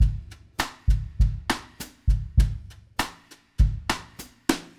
Вложения Tcpview.zip Tcpview.zip 146,4 KB · Просмотры: 132 Снимок.JPG 9,5 KB · Просмотры: 118 bossa2 100bpm loop.wav bossa2 100bpm loop.wav 827 KB · Просмотры: 133